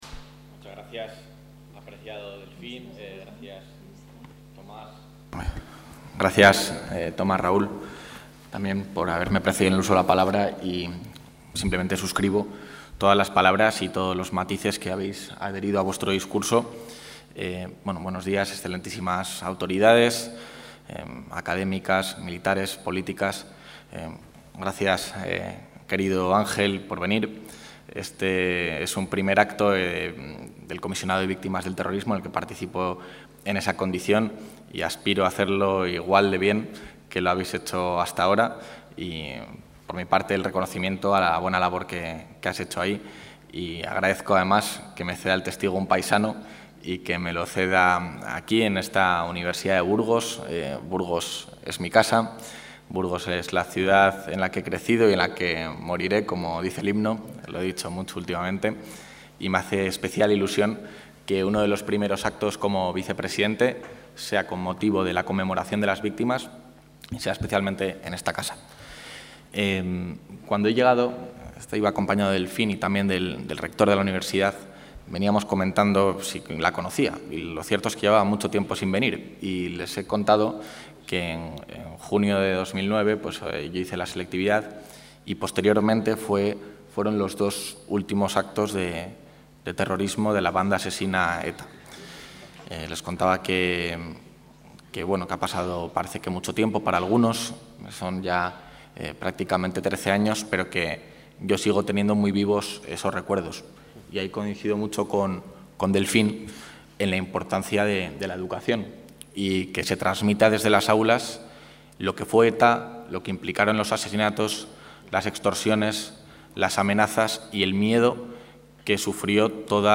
Intervención vicepresidente.
El vicepresidente de la Junta de Castilla y León, Juan García-Gallardo, ha inaugurado hoy en Burgos las jornadas 'Memoria y prevención del terrorismo: Educación y competencias para una ciudadanía y cultura democráticas'.